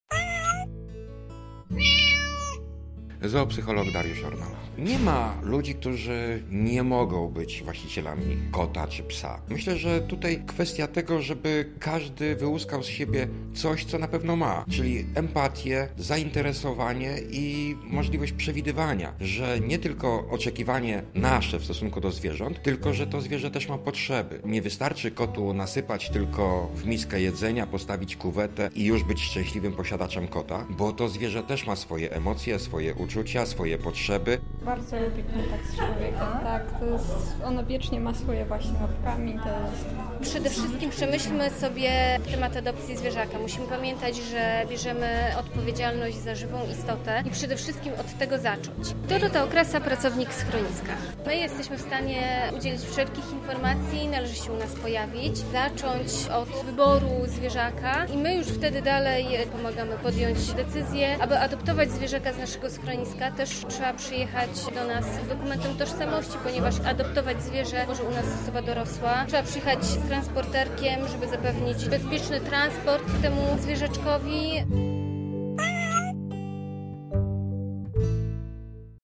Dzień Kota w lubelskim Schronisku dla Zwierząt
Wolontariusze mówili też o odpowiedzialnej adopcji: